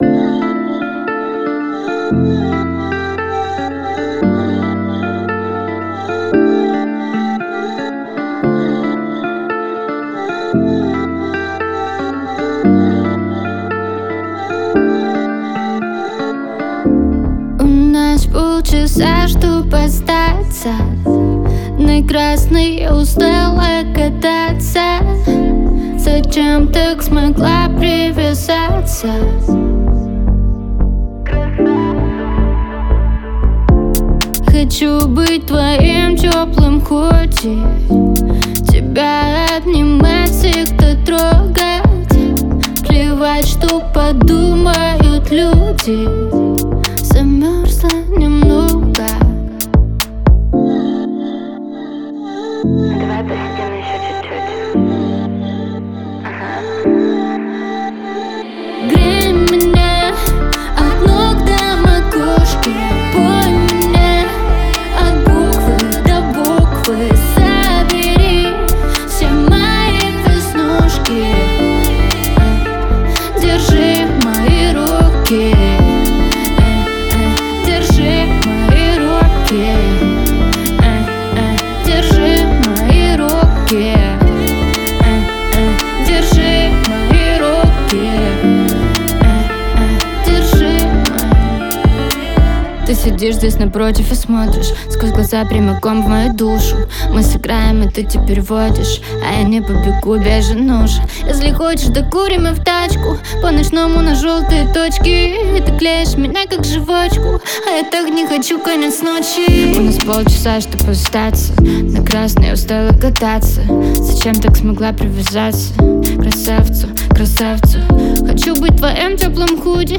это яркая и жизнерадостная песня в жанре поп